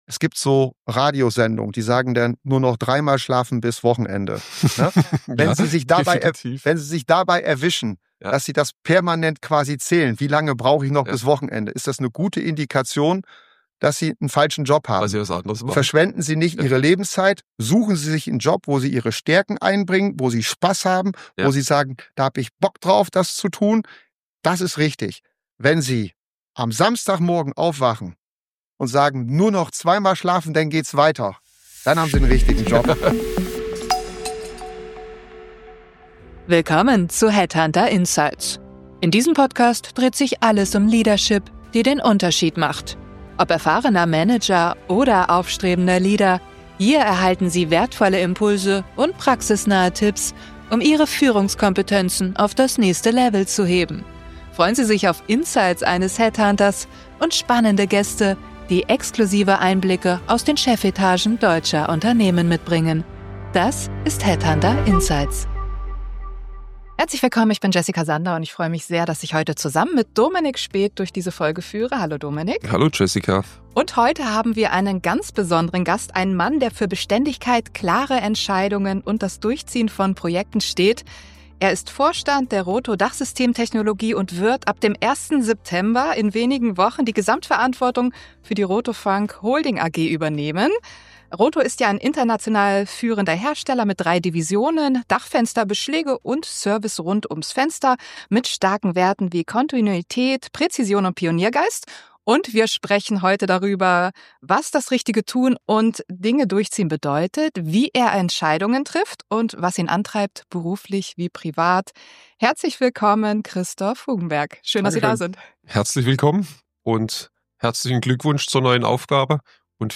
Ein Gespräch über Ausdauer, Vertrauen und konsequente Entscheidungen.